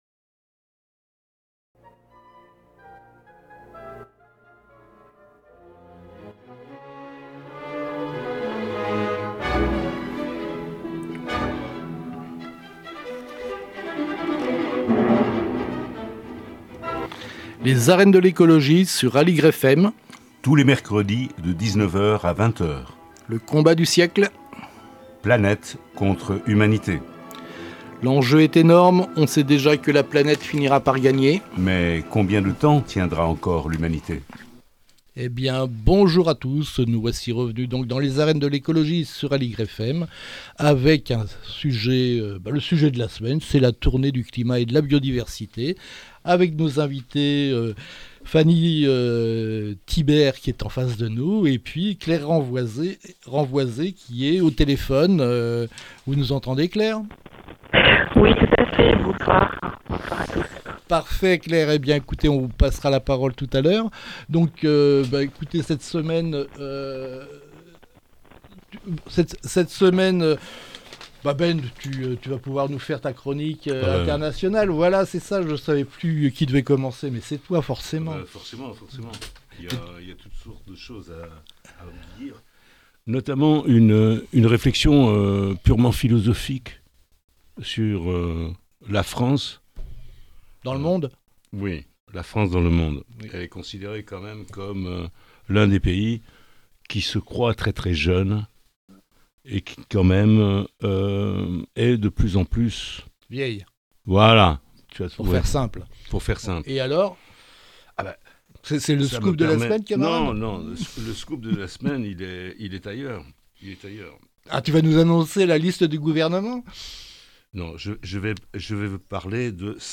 Les arènes de l'écologie, tous les mercredis de 19:00 à 20:00 sur Aligre FM.